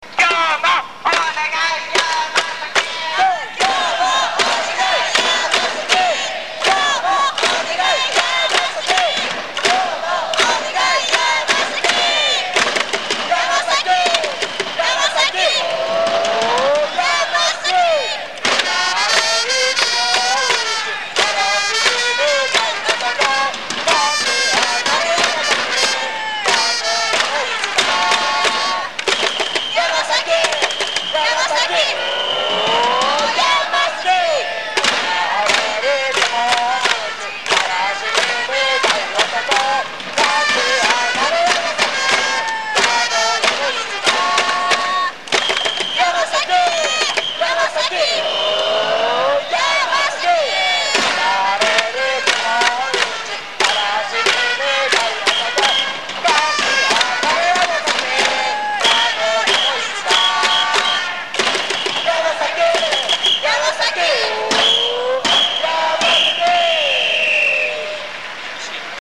応援歌を歌うときの基本
生音声（mp3） MIDI
メガホンの叩き方は通常。
注意点は歌い出し。